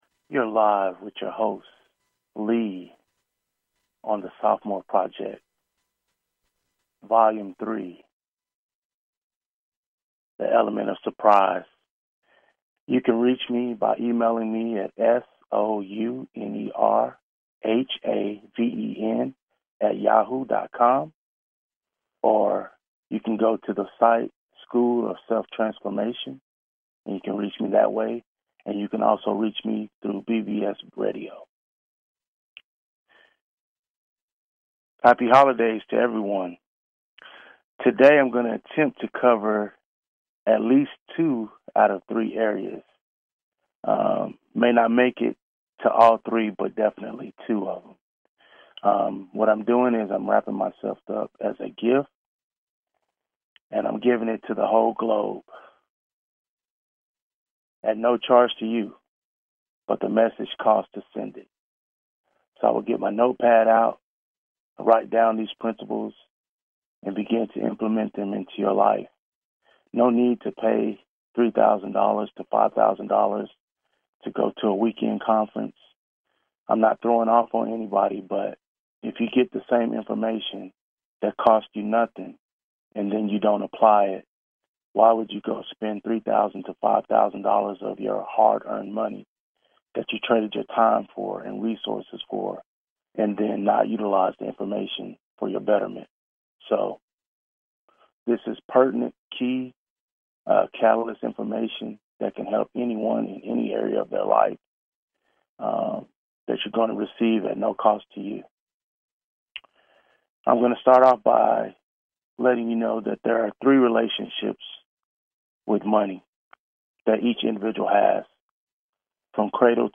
Sudden I Impact (sii) is a talk show about discovery, helping people raise their awareness and identify their gifts, finding things in life they enjoy doing, finding their uniqueness, and potentially turning their passions into businesses that thrive, and most of all, living life by plan and design to earn a living doing what they love (the essential thing).